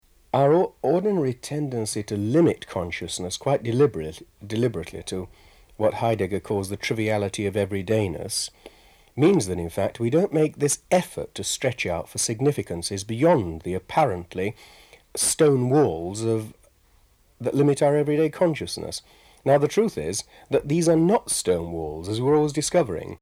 Then we get the recorder out and the glasses are re-filled. Time for some serious interviewing.